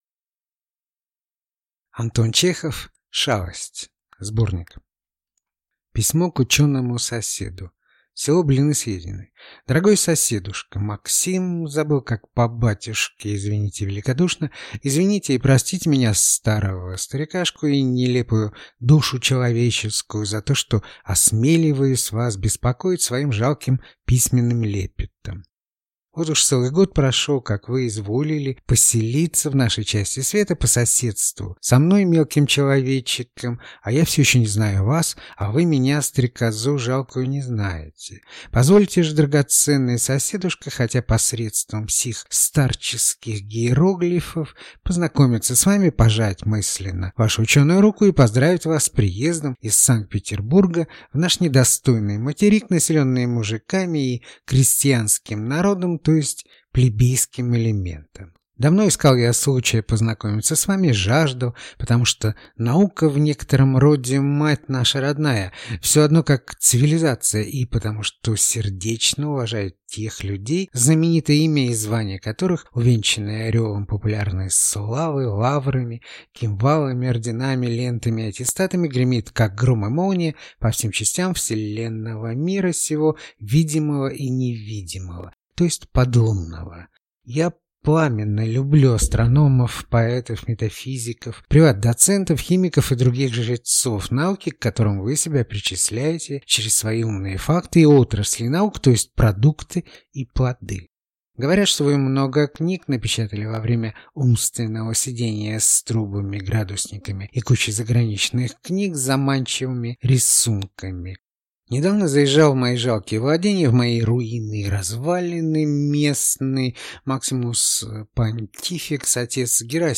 Аудиокнига Шалость (сборник) | Библиотека аудиокниг